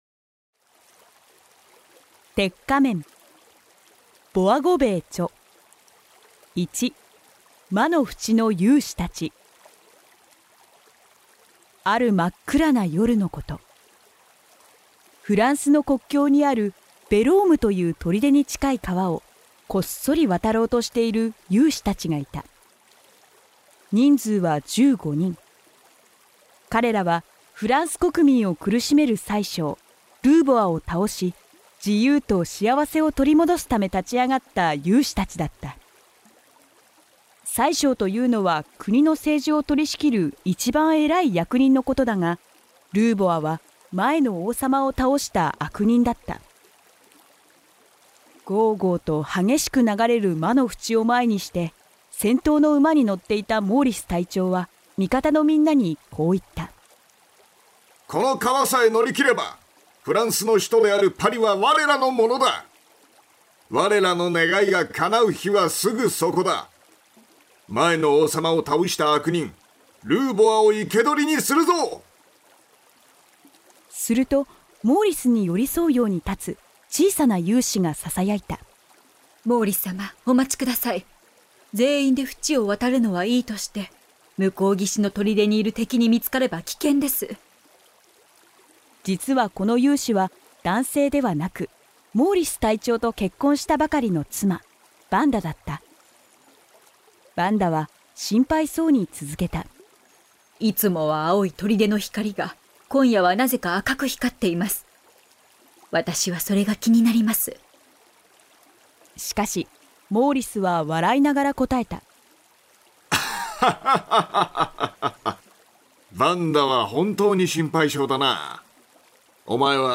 [オーディオブック] 鉄仮面（こどものための聴く名作 26）